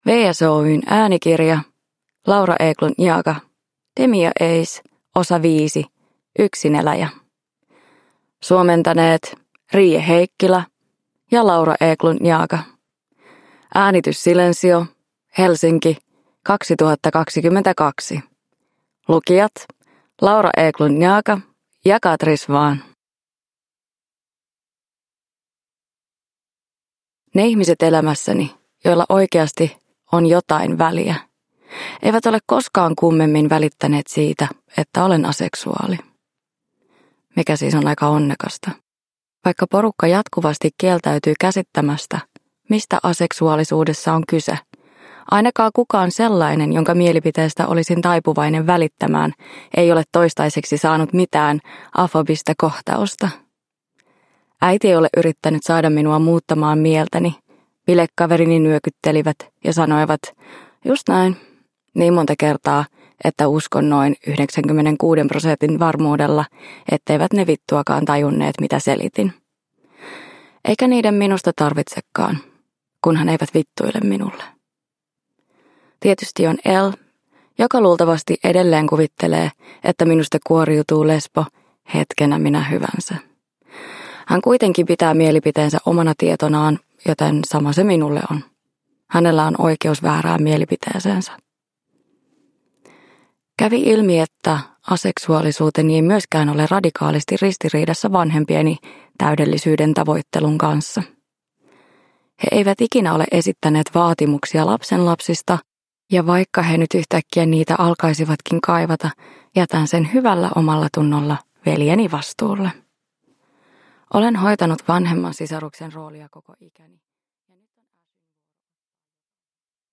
Demi & Ace 5: Yksineläjä – Ljudbok – Laddas ner
Raikas kuusiosainen audiosarja siitä kuinka outoa rakkaus on.